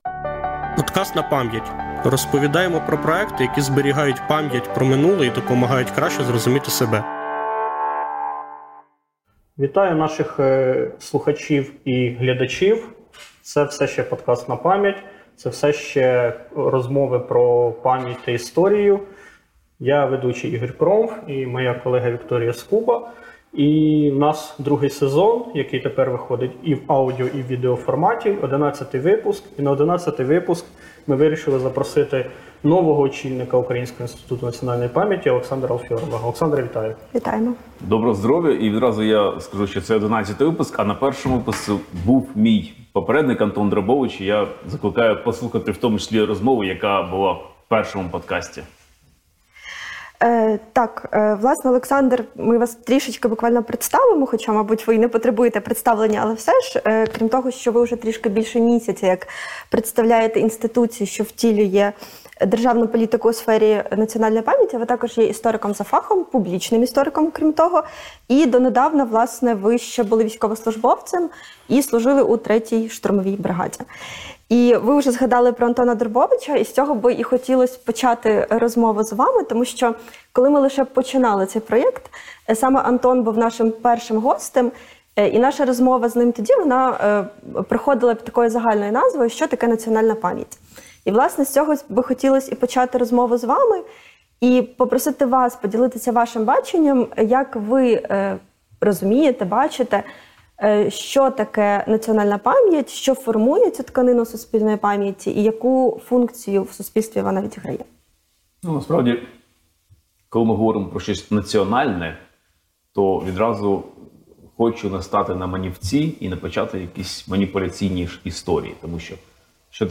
Розмова з новим головою Українського інституту національної памʼяті істориком Олександром Алфьоровим.